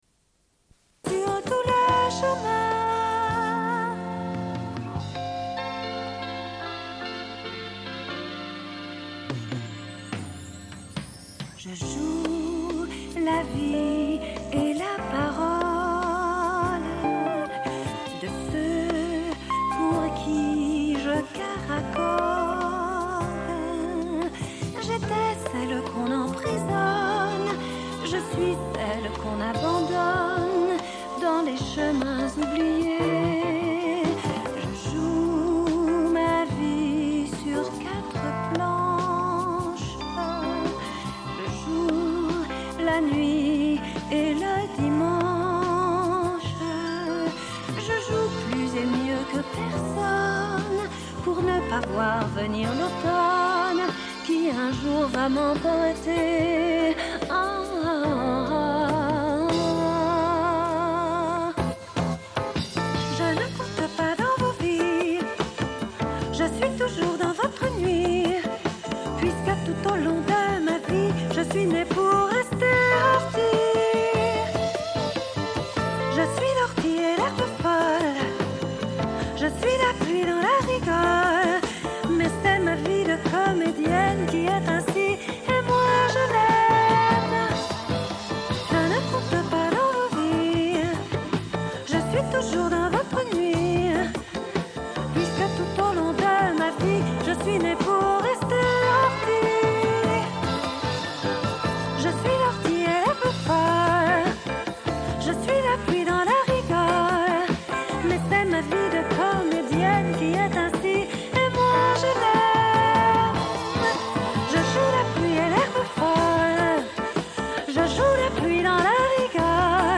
Une cassette audio, face A31:44